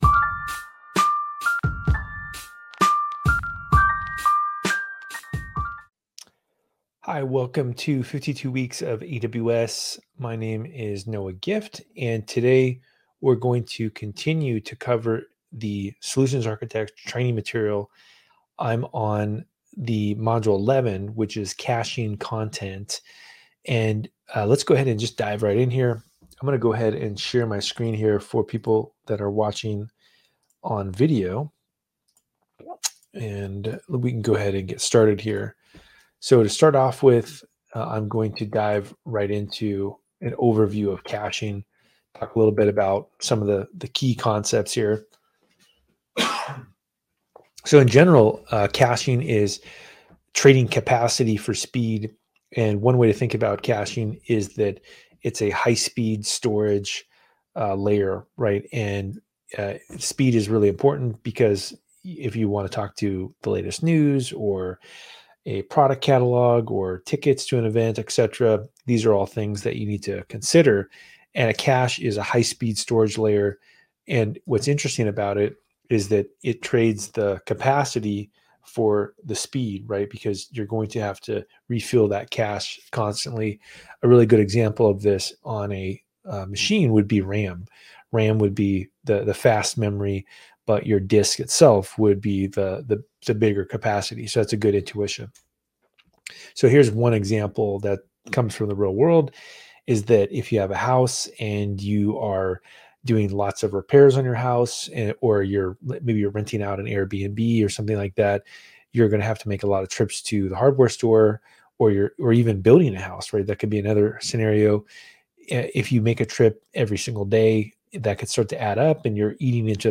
Download - The Little Data Thief Who Could: Chapter Seven-An Eyeball for Data Theft (Narrated with Cloned Voice) | Podbean